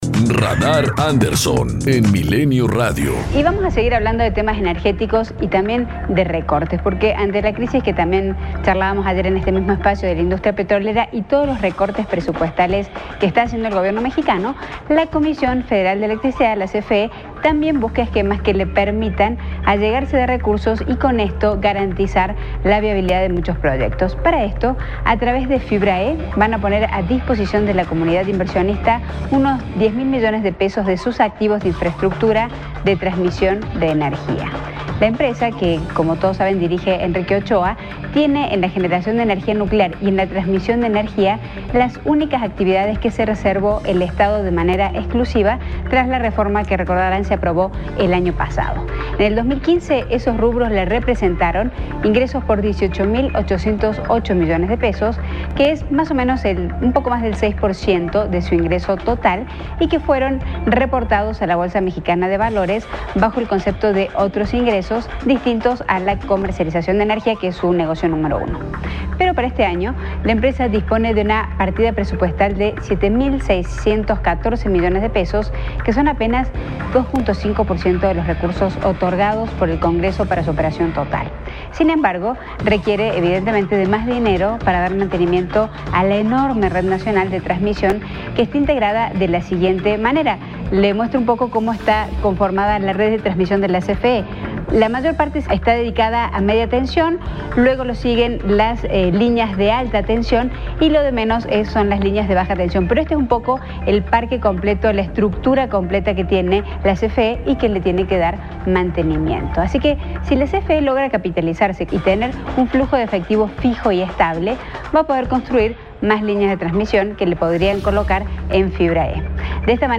COMENTARIO EDITORIAL 100316